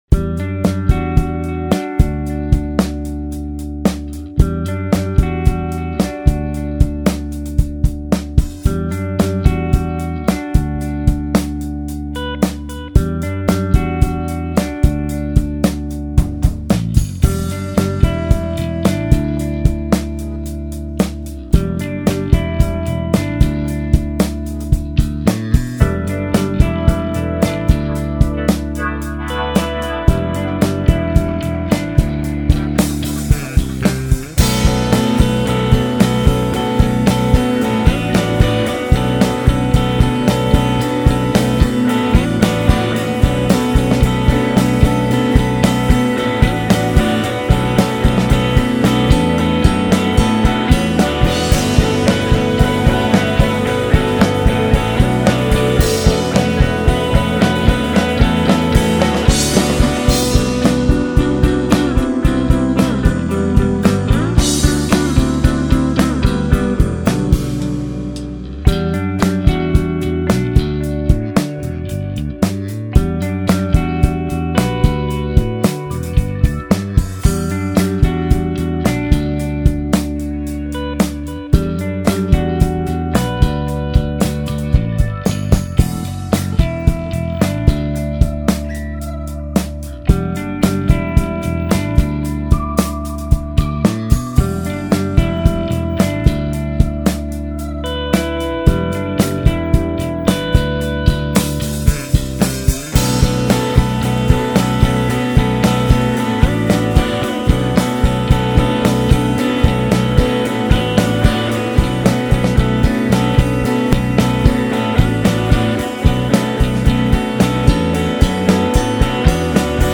guitars, electronics, keyboards, bass
bass, guitars, keyboards
drums, guitars